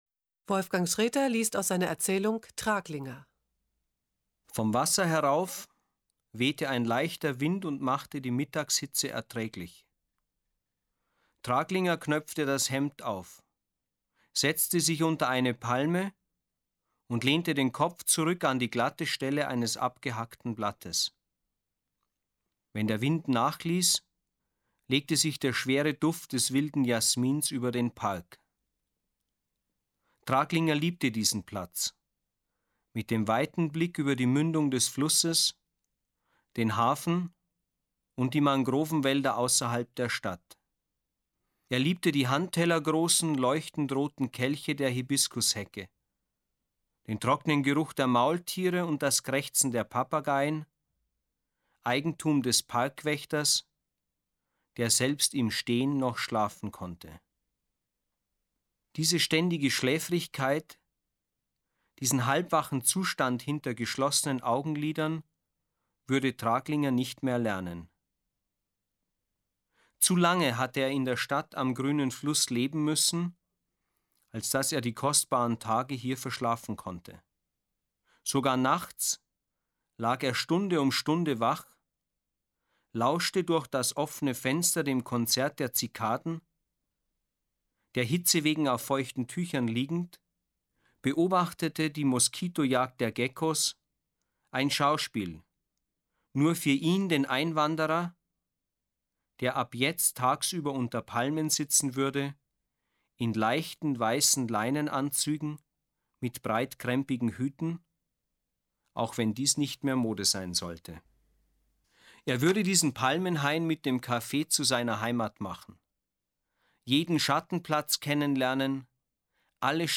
Es umfasst 40 CDs, auf denen insgesamt 573 Lesungen enthalten sind.